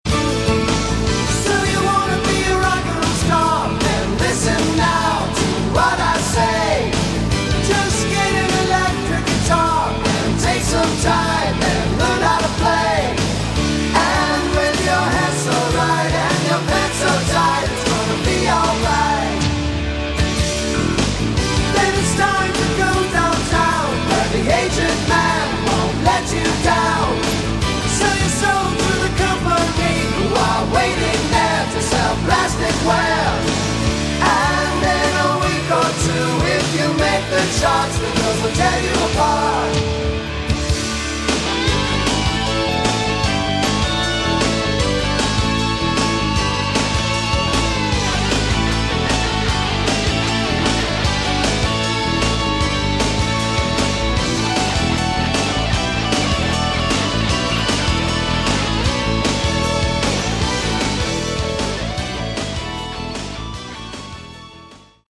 Category: AOR
It's poppy, synth , 80's sound like aor.